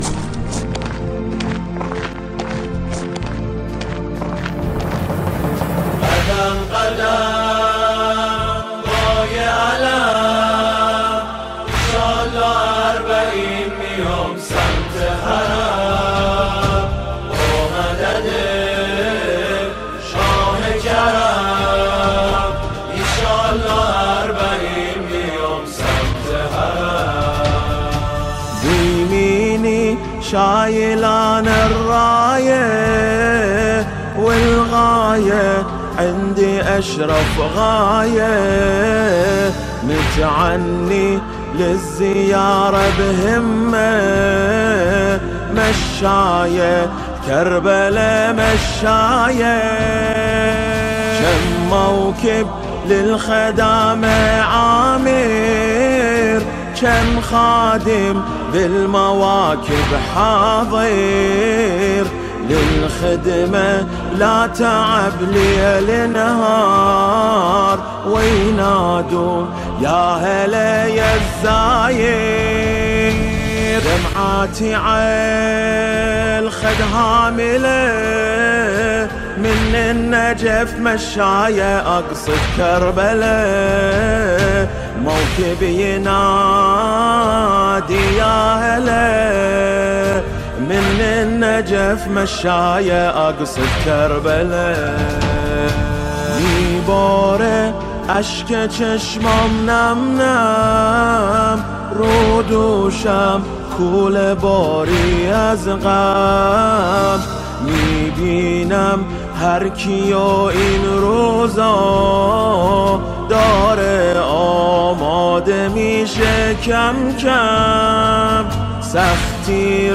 به مناسبت پیاده روی اربعین